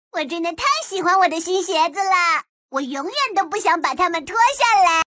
AI för Övertygande Talröst
Fånga uppmärksamheten med en kraftfull, övertygande AI-röst skräddarsydd för debatter, keynote-tal och viktiga presentationer.
Text-till-tal
Befallande Ton
Retorisk Betoning